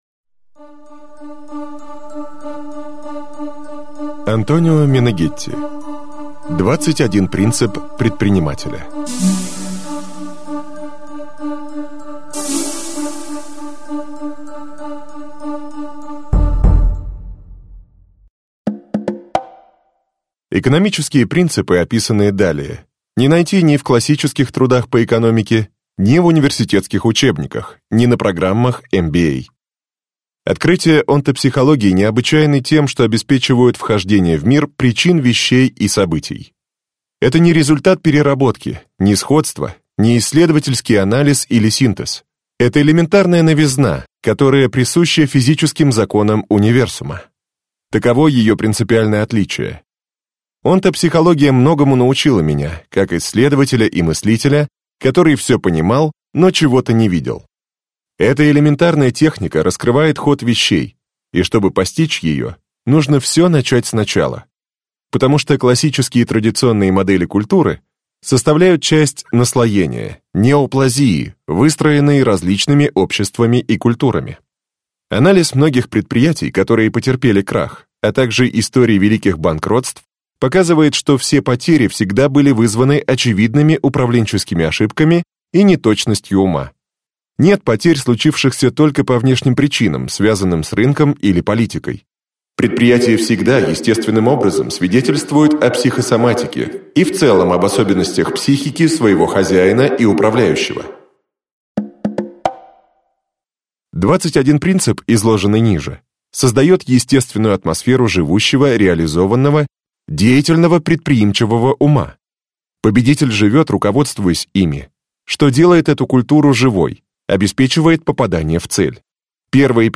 Аудиокнига 21 принцип предпринимателя | Библиотека аудиокниг